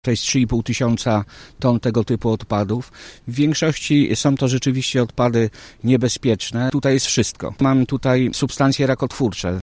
Odpady są niebezpieczne dla zdrowia. Potwierdził to Mirosław Ganecki, Wojewódzki Inspektor Ochrony Środowiska: